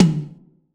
Closed Hats
pcp_tom03.wav